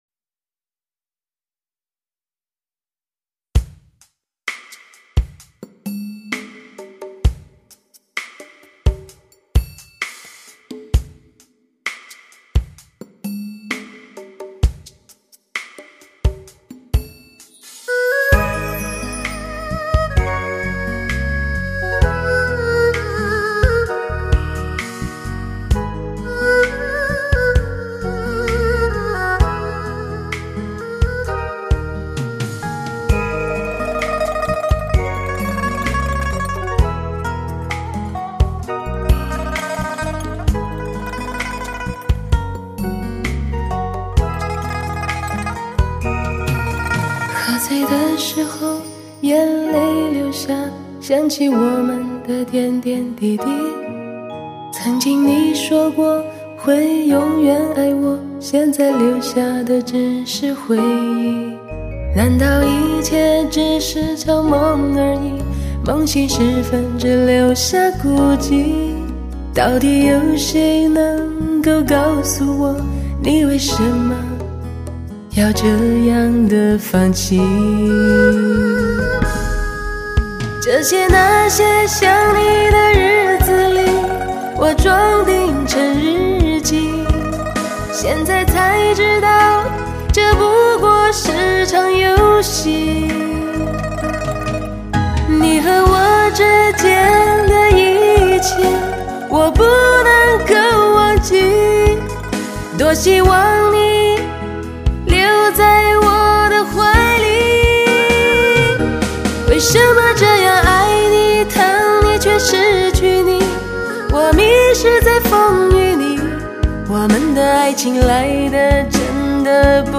专业发烧汽车唱片　LP-CD德国黑胶
改变唱片命运的革新技术　HD技术录音
动听绝伦的人声飨宴　无法抗拒的奢华诱惑